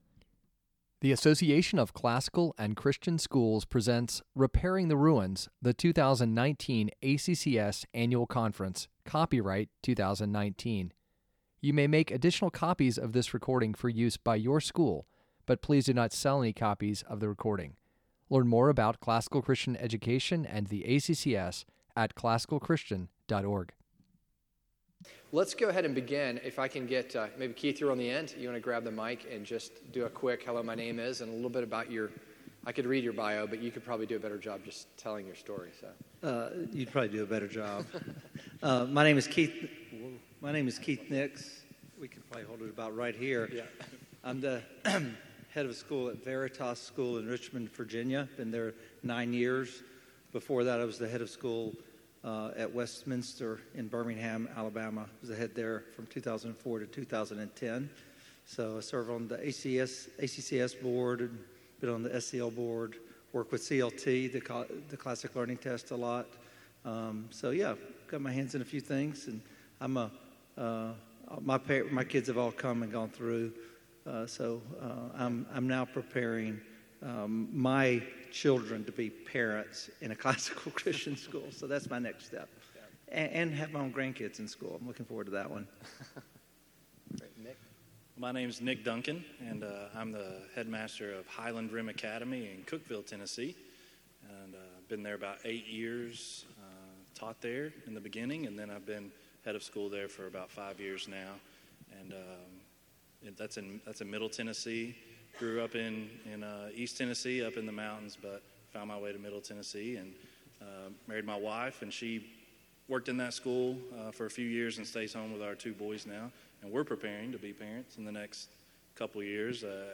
Panel Discussion: Educating Parents About Classical Christian Education
2019 Leaders Day Talk | 50:10 | Leadership & Strategic, Student Products & Services